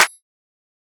MZ Clap [Plugg Alt].wav